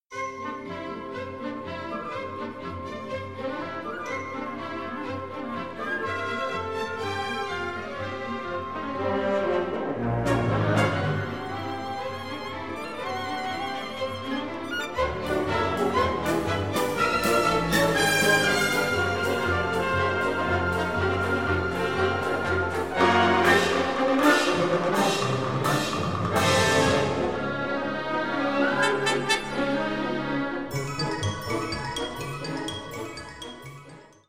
Sinfonische Dichtung